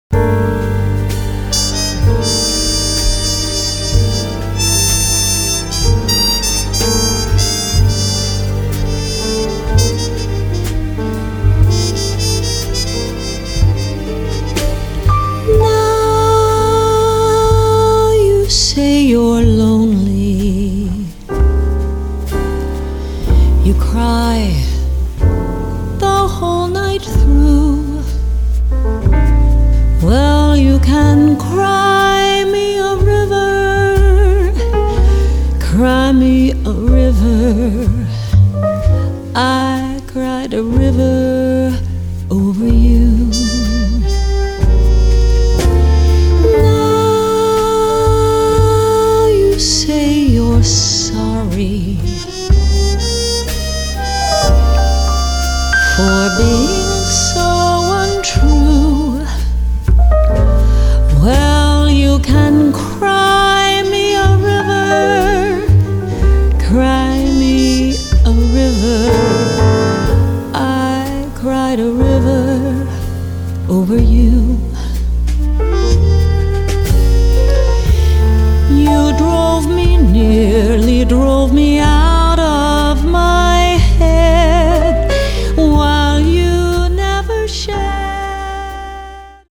jazz vocalist